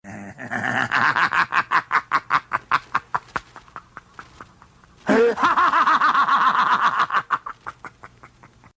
Fred Krueger - Смех Фредди Крюгера
Отличного качества, без посторонних шумов.
368_smeh-freddy-kru.mp3